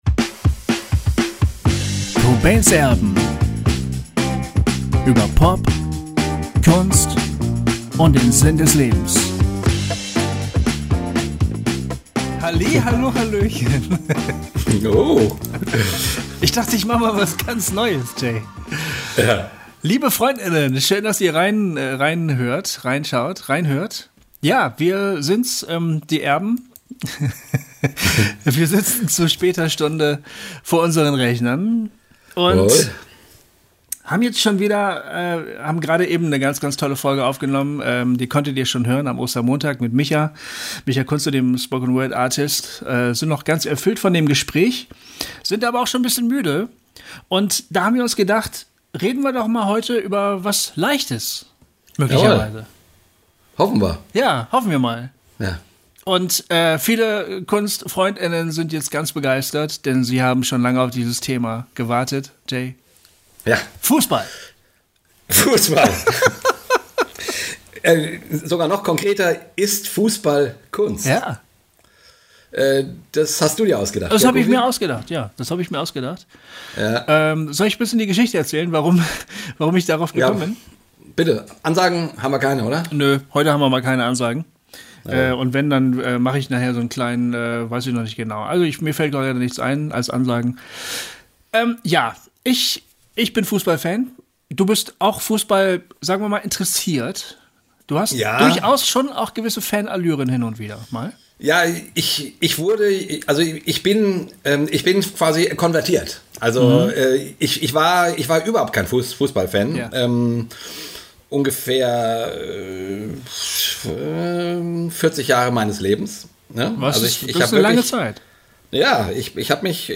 Dies ist ein Talk nicht nur für Fußballfans!